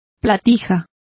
Complete with pronunciation of the translation of flounder.